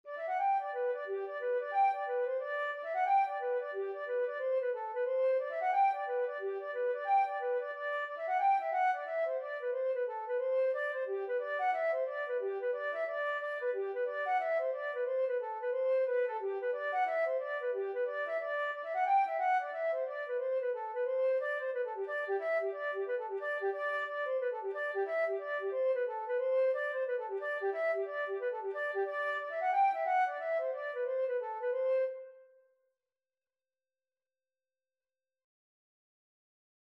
G major (Sounding Pitch) (View more G major Music for Flute )
4/4 (View more 4/4 Music)
G5-G6
Flute  (View more Easy Flute Music)
Traditional (View more Traditional Flute Music)